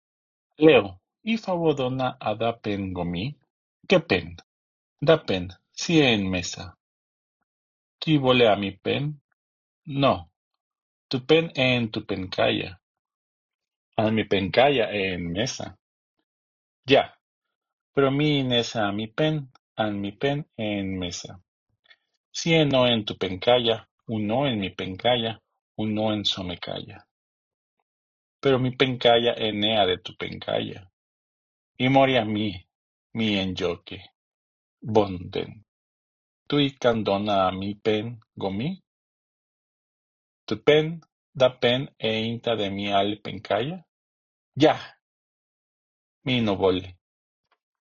Conversation time: